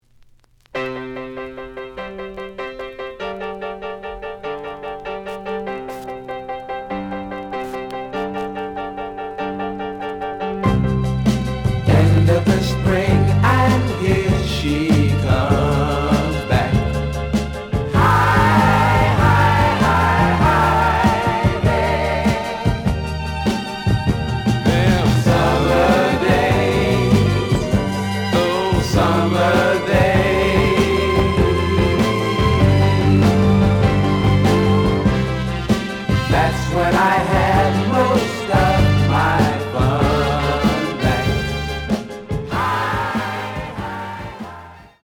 The audio sample is recorded from the actual item.
●Genre: Funk, 60's Funk
Some noise on parts of both sides.)